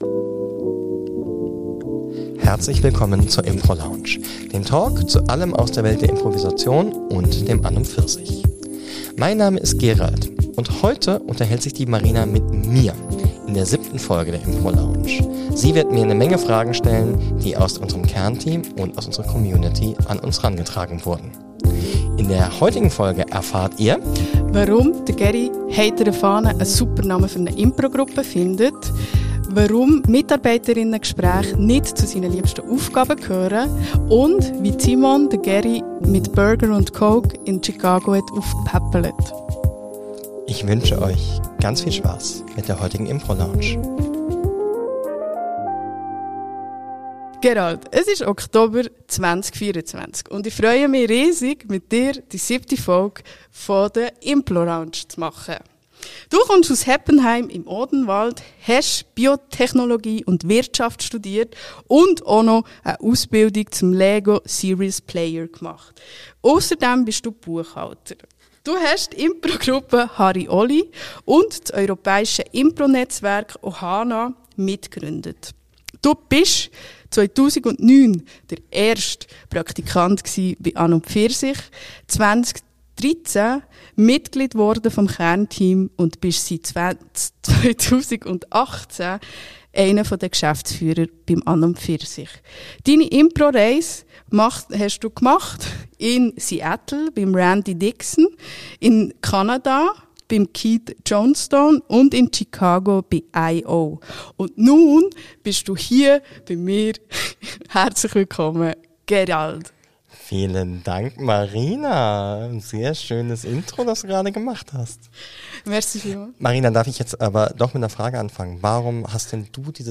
One-on-One